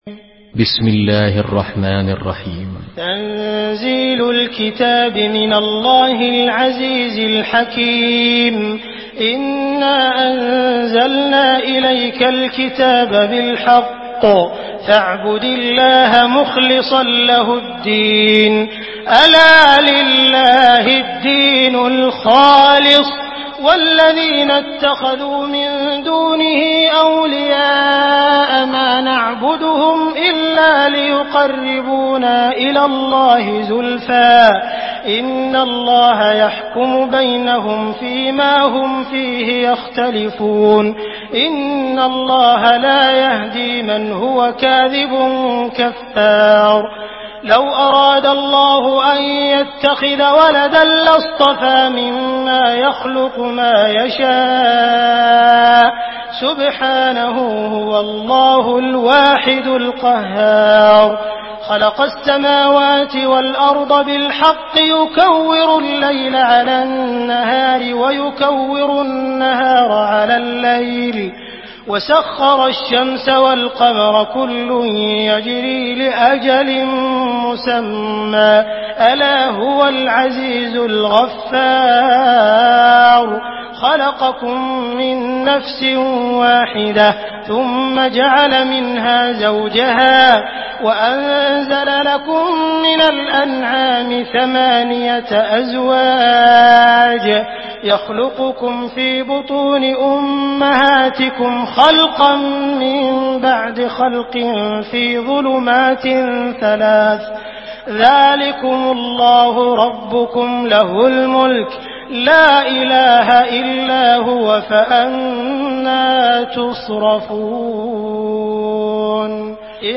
Surah Zümer MP3 in the Voice of Abdul Rahman Al Sudais in Hafs Narration
Surah Zümer MP3 by Abdul Rahman Al Sudais in Hafs An Asim narration.
Murattal Hafs An Asim